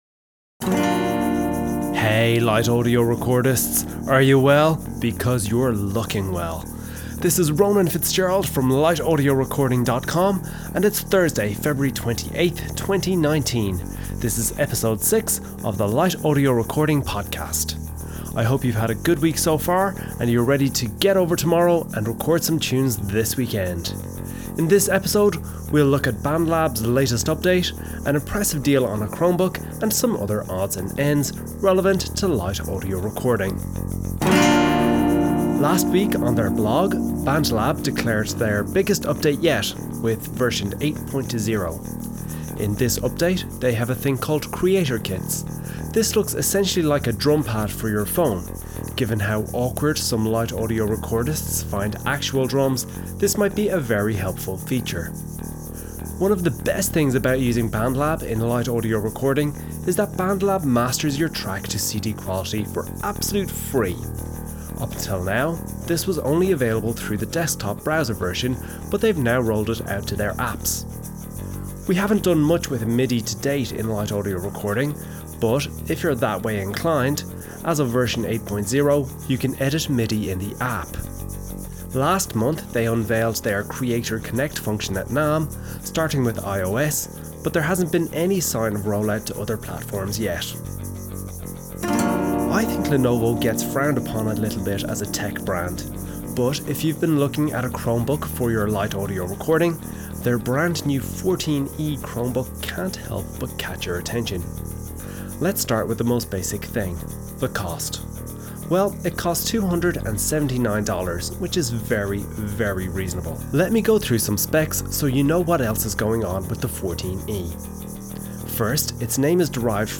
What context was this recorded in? However, I resolved it by simplifying things and just using the Studio GT while I was recording. Afterwards, I used the BT3 for editing, mixing, and mastering.